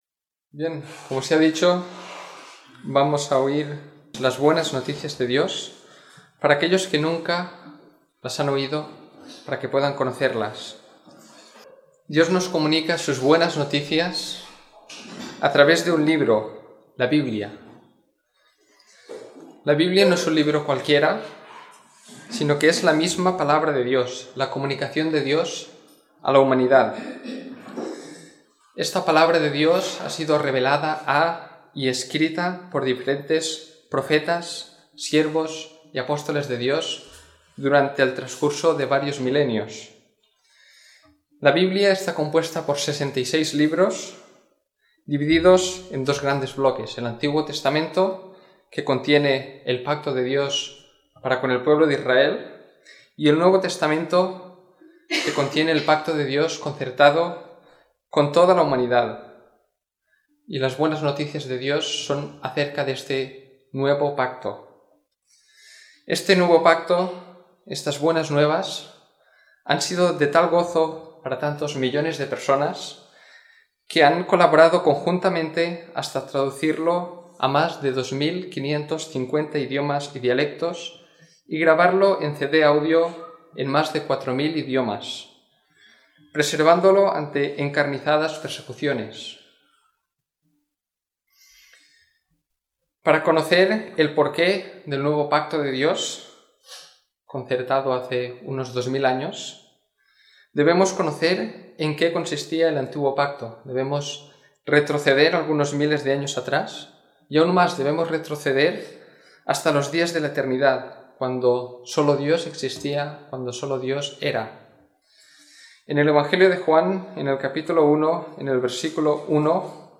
Domingo por la Mañana